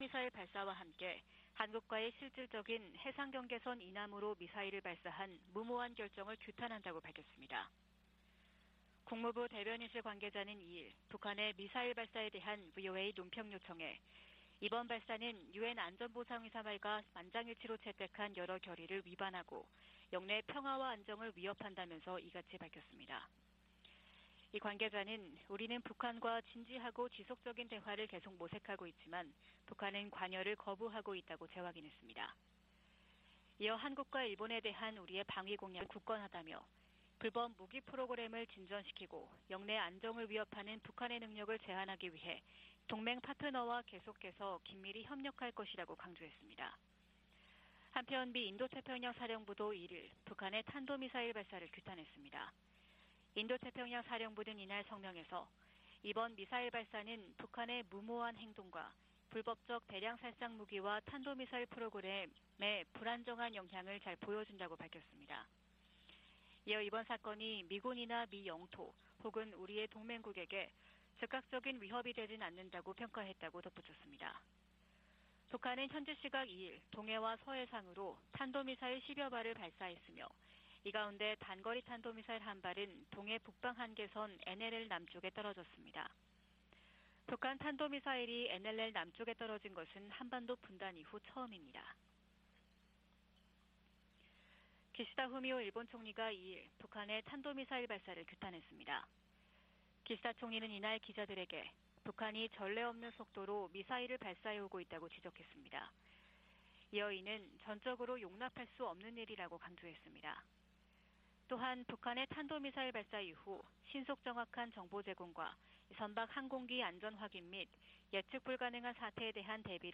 VOA 한국어 '출발 뉴스 쇼', 2022년 11월 3일 방송입니다. 북한 정권이 남북 분단 이후 처음으로 동해 북방한계선 NLL 이남 한국 영해 근처로 탄도미사일을 발사하는 등 미사일 수십 발과 포병 사격 도발을 감행했습니다.